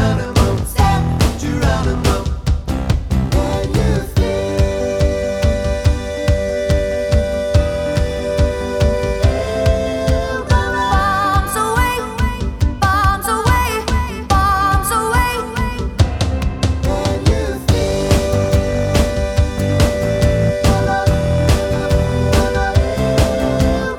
for duet Pop (2010s) 3:37 Buy £1.50